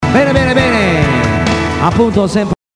telefonate e altre "chicche"